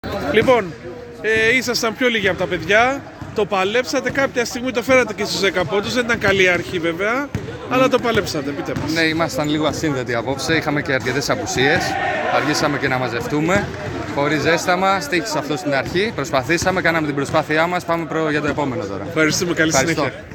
GAME INTERVIEWS